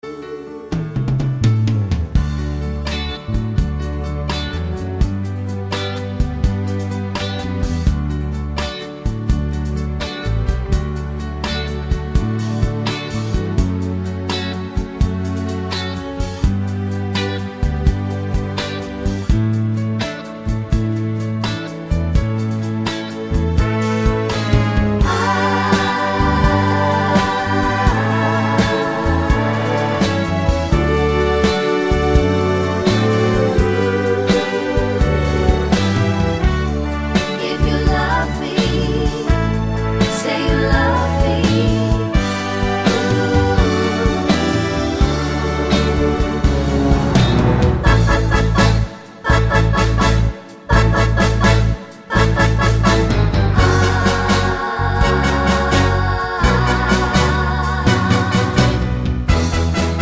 no Backing Vocals Crooners 3:10 Buy £1.50